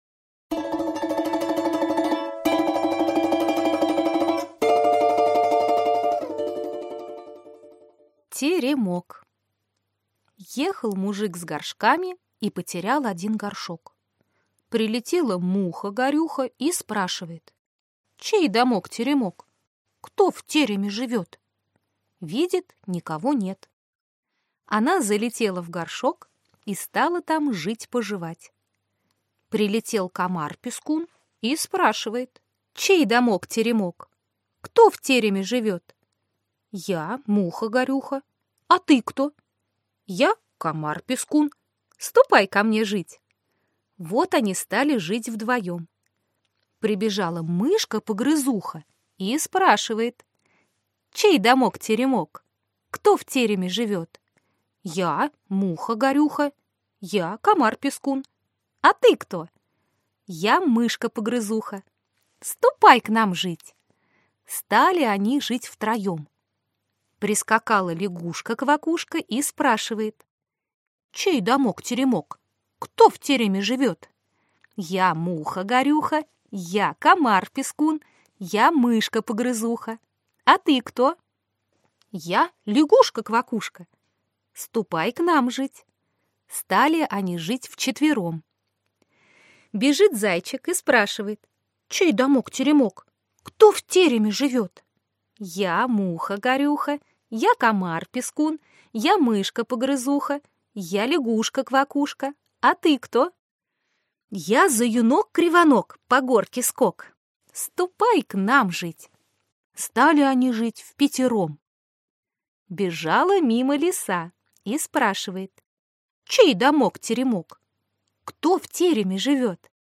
Аудиокнига Теремок | Библиотека аудиокниг